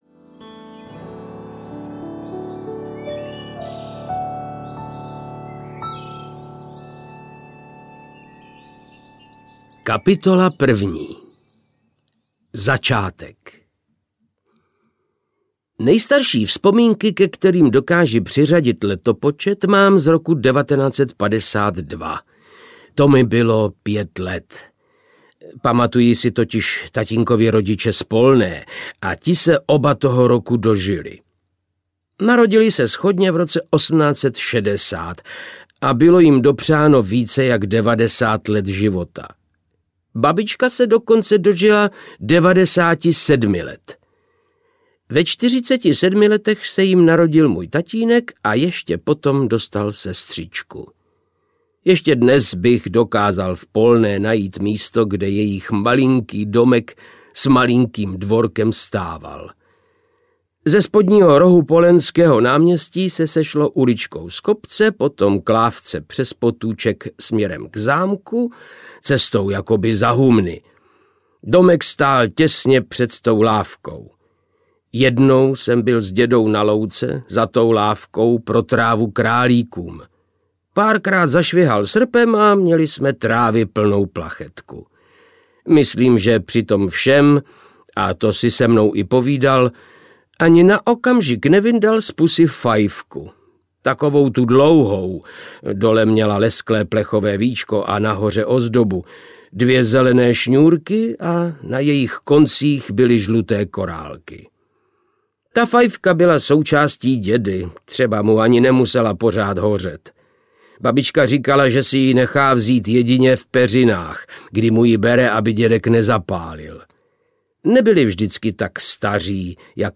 Šlápoty audiokniha
Ukázka z knihy
• InterpretVáclav Knop